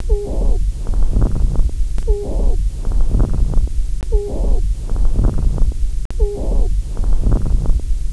Chest Auscultation
The following sound was heard over her right lung: chest sound.
rtlung.wav